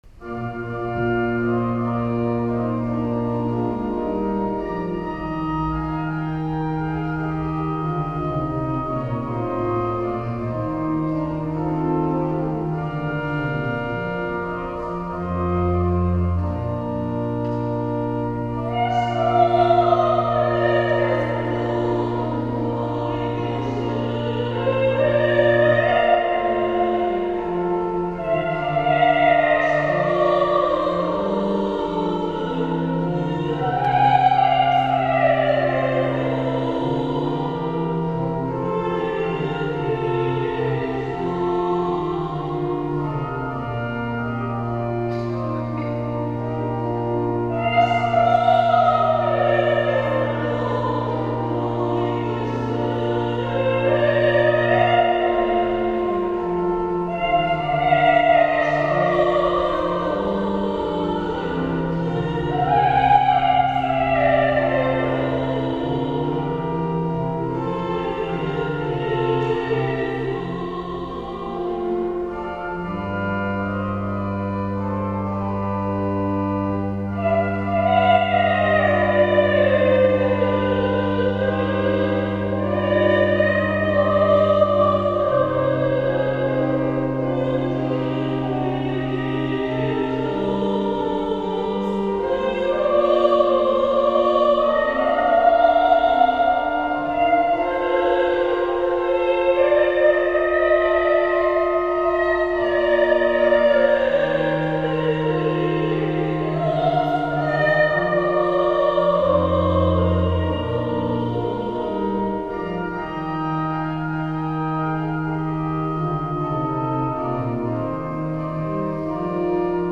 Posłuchaj mnie - utwory wykonane z towarzyszeniem organów